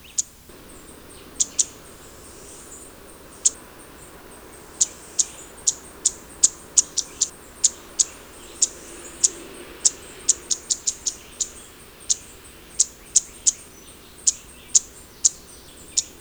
"Reinita Trepadora"
"Black-and-white Warbler"
Mniotilta varia
reinita-trepadora.wav